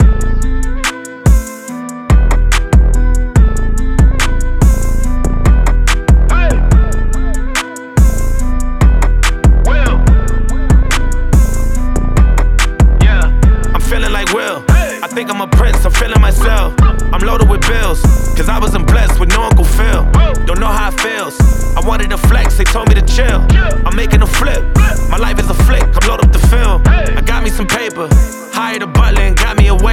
• Rap